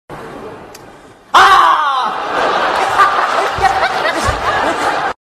SFX宋小宝啊的一声音效下载
SFX音效